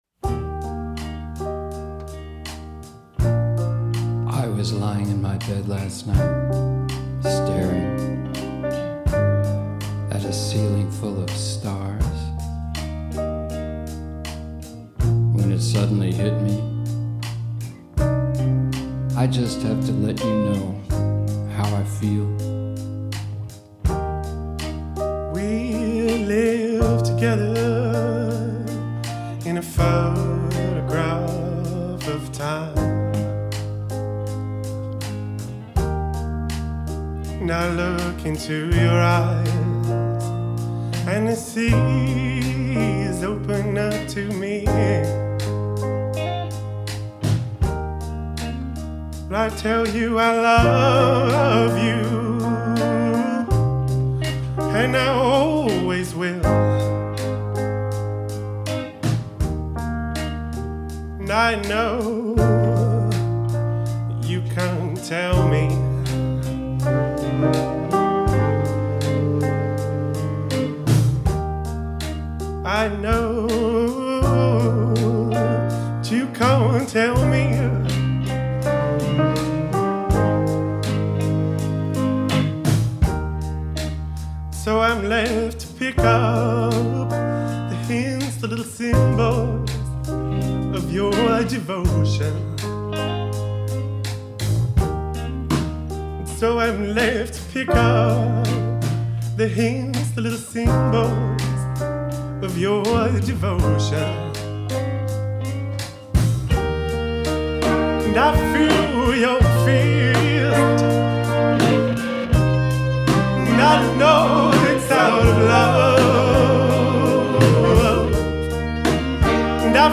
I can has piano, some jazzy guitar, and a sax or two?